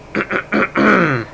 Clear Throat Sound Effect
Download a high-quality clear throat sound effect.
clear-throat.mp3